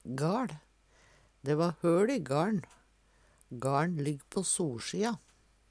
gaL - Numedalsmål (en-US)